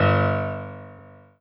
piano-ff-11.wav